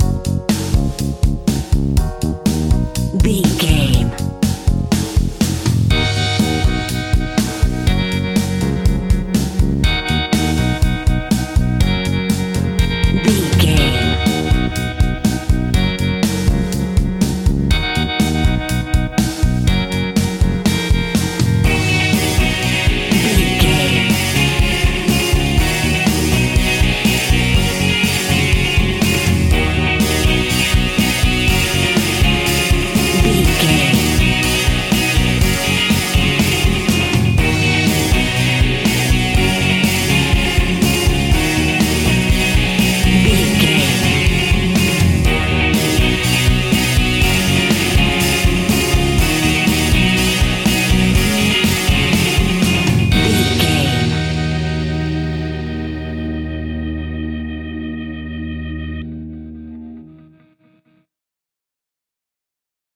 Ionian/Major
E♭
pop rock
indie pop
fun
energetic
uplifting
electric guitar
Distorted Guitar
Rock Bass
Rock Drums
hammond organ